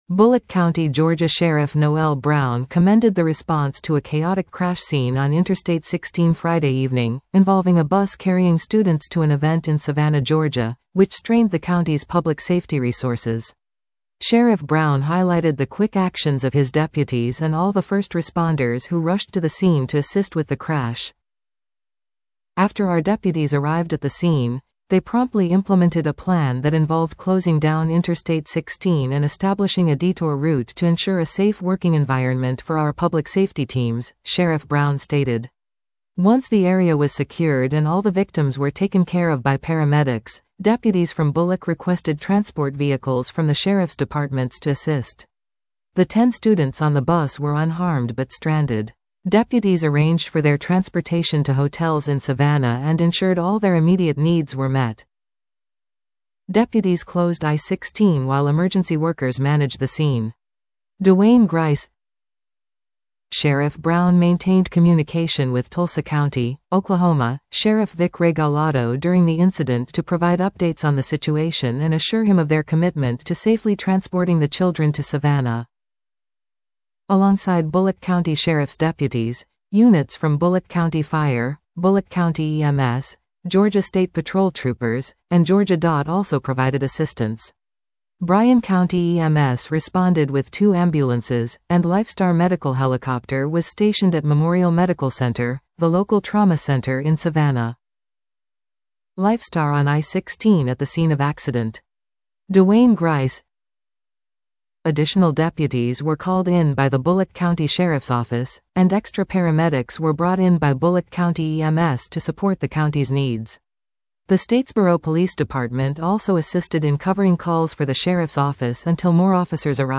Bulloch Sheriff describes chaotic bus crash scene that taxed resources